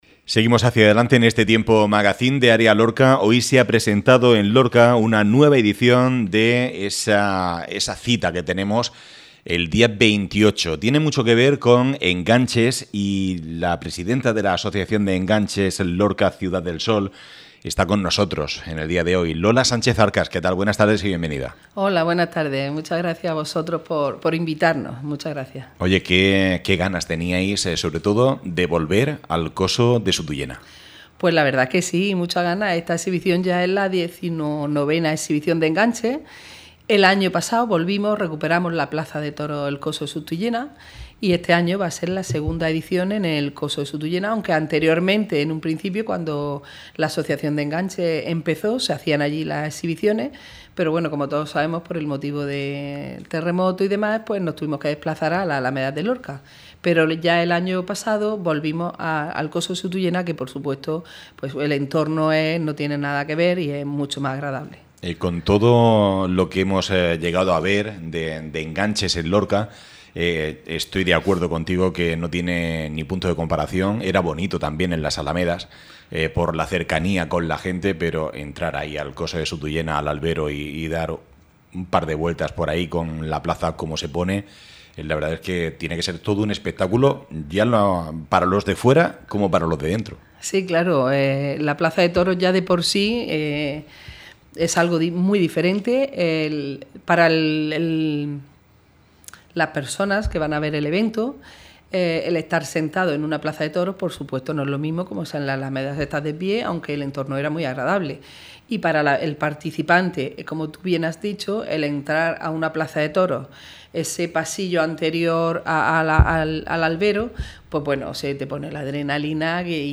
en el magazine de Área Lorca Radio